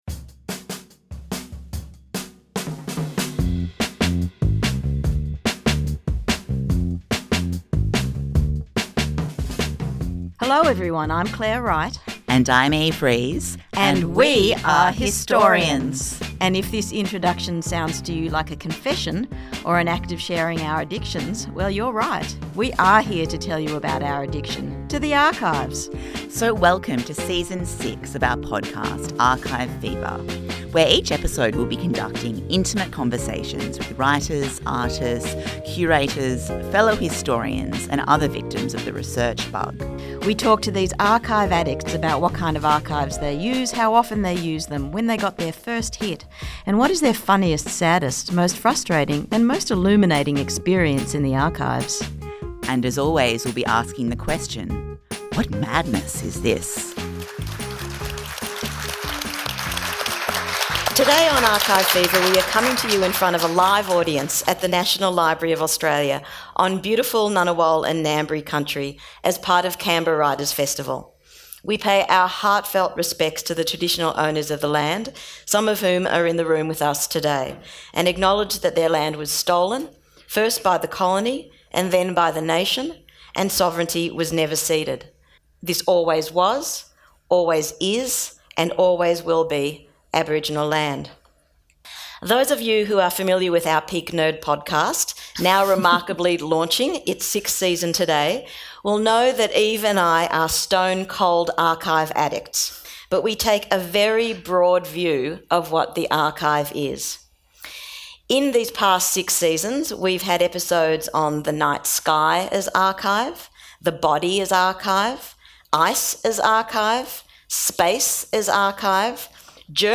43 | Language as Archive (Live at the Canberra Writers Festival)